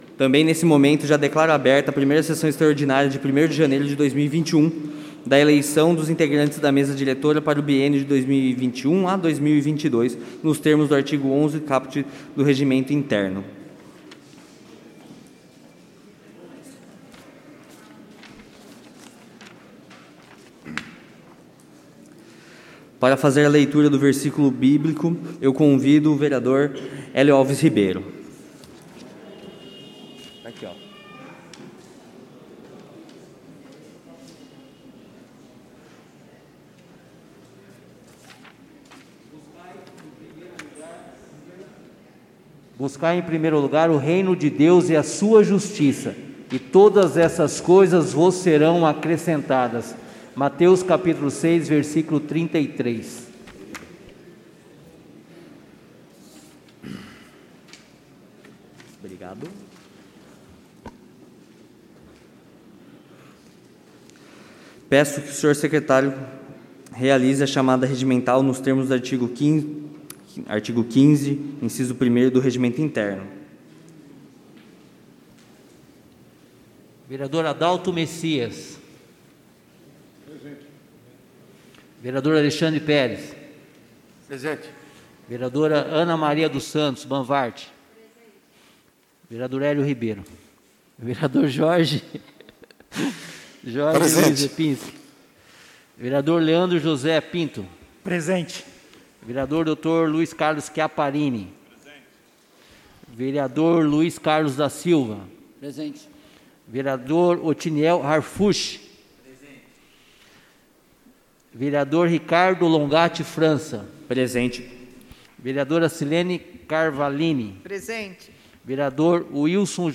Audio Sessao Extra_Eleicao Mesa Diretora